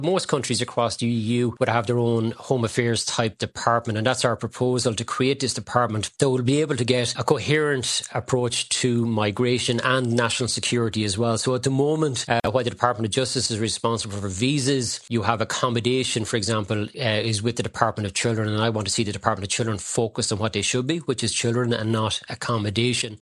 Deputy James Browne, Minister of State for International Law Reform and Youth Justice at the Department of Justice, is part of Fianna Fáil’s negotiating team.